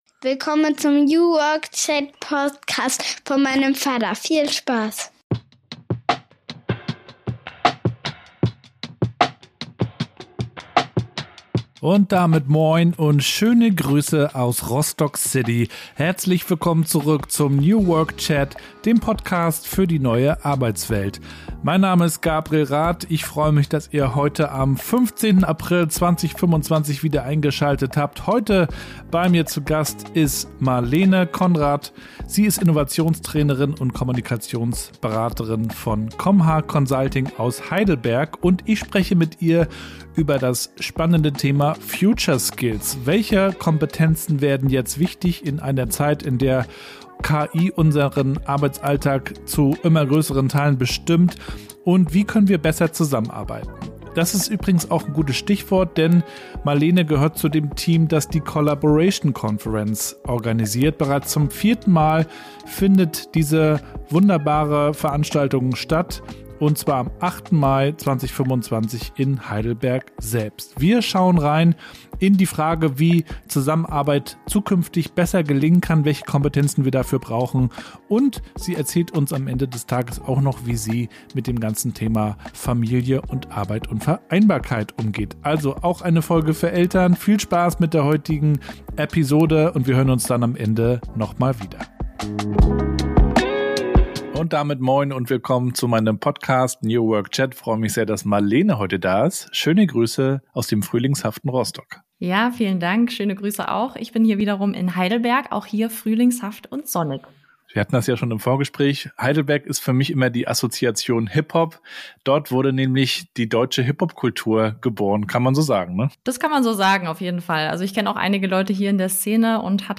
In meinem Podcast „New Work Chat“ interviewe ich spannende Köpfe rund um die Themen New Work, Kultur und Transformation. Wie finden wir eine Arbeit, die uns erfüllt und einen Unterschied macht?